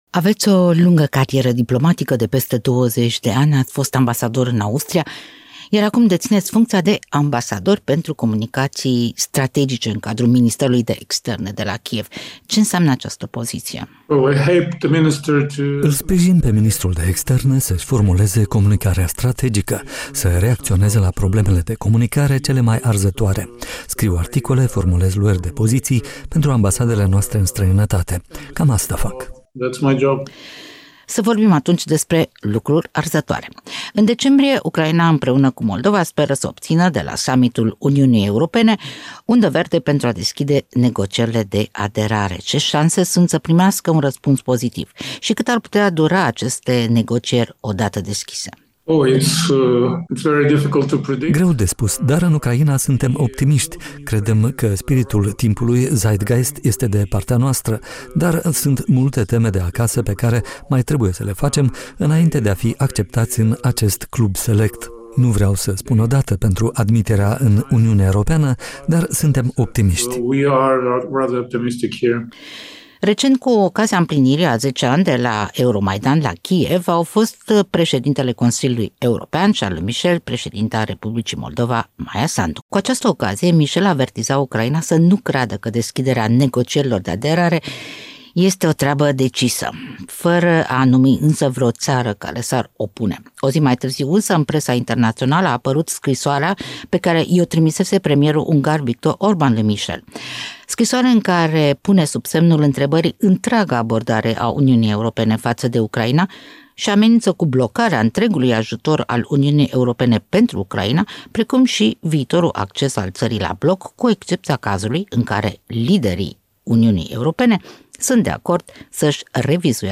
Interviu cu Olexander Scherba, ambasadorul ucrainean pentru comunicații strategice